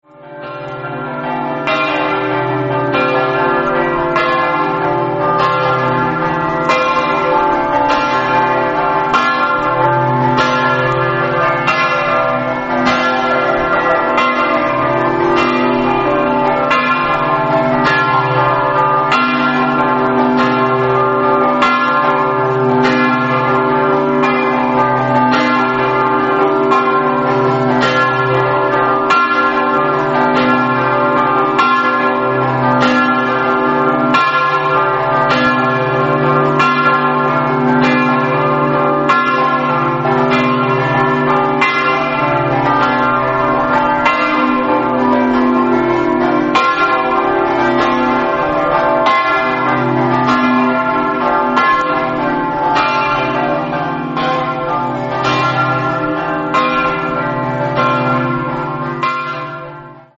SCAMPANOTTATA